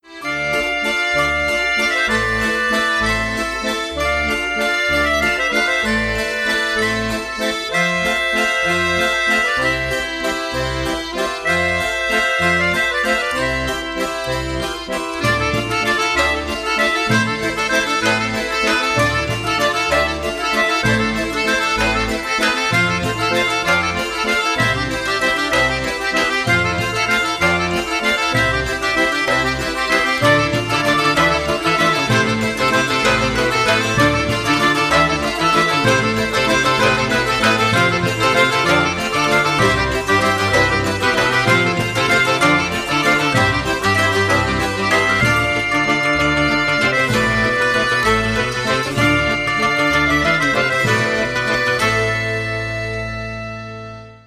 • Качество: 320, Stereo
без слов
инструментальные
инструментальная музыка